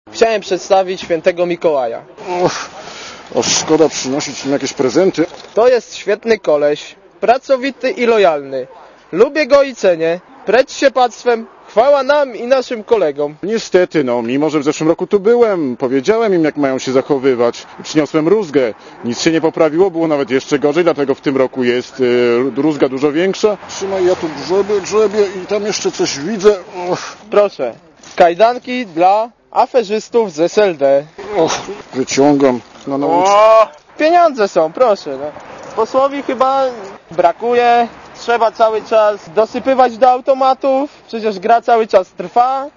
Happening Mikołajkowy zorganizowała Młodzieżówka Prawa i Sprawiedliwości.
(RadioZet) Źródło: (RadioZet) Przy mikrofonie Święty Mikołaj Oceń jakość naszego artykułu: Twoja opinia pozwala nam tworzyć lepsze treści.